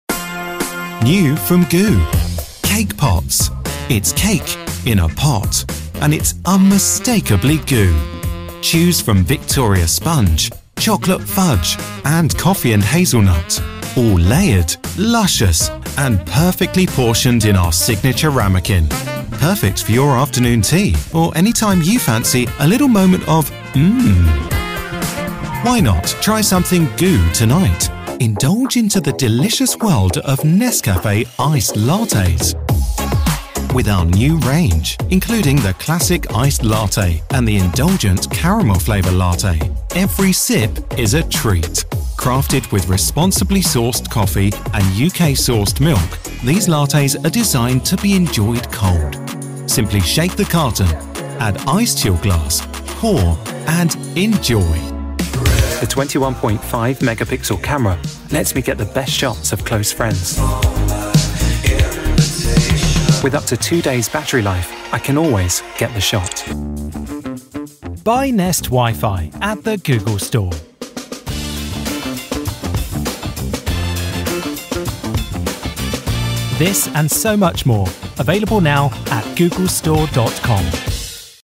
Showreel
Male / 30s / English / Southern Showreel http